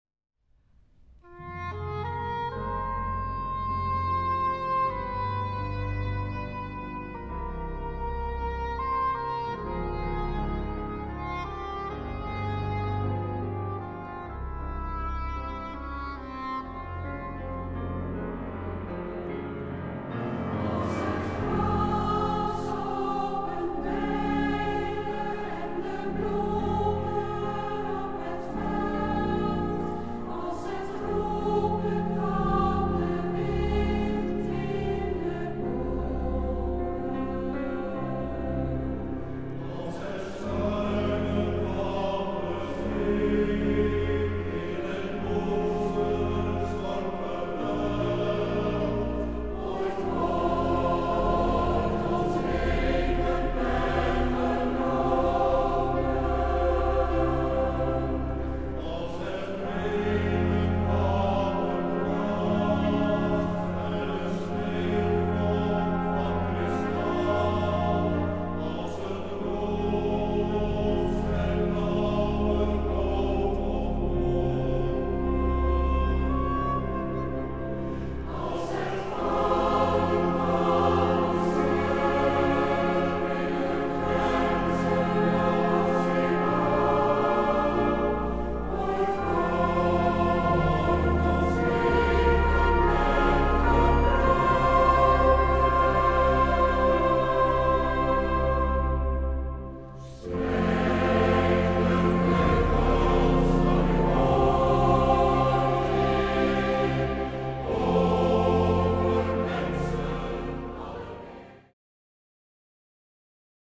orgel
piano
fluit
hobo
trompet
slagwerk